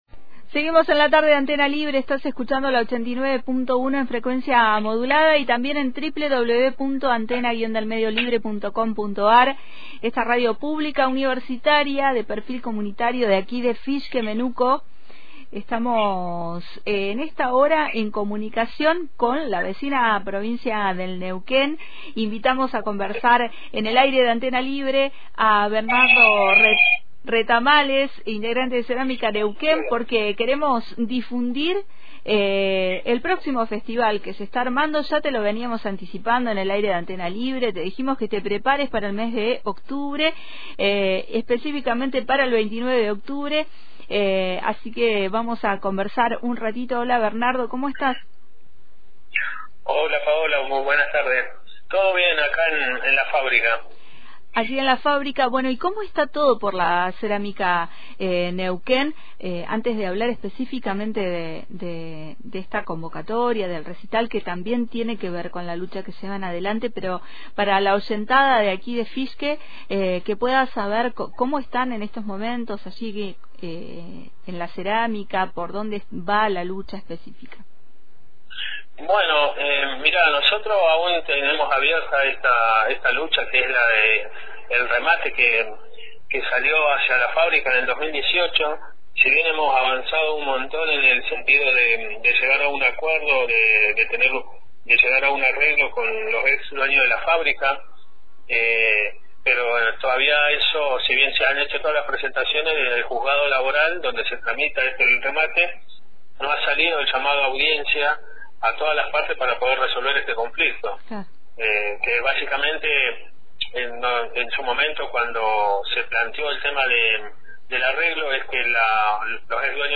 Entrevistamos en vivo